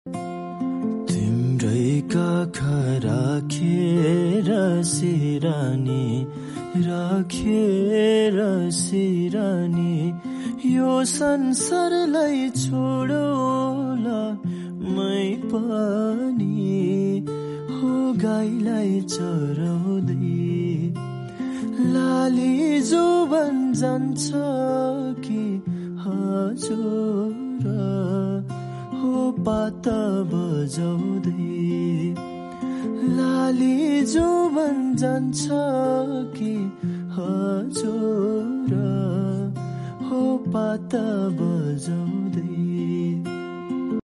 raw cover